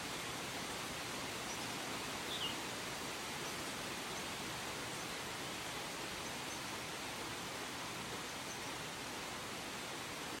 Sonido de la lluvia en Upala ALAJUELA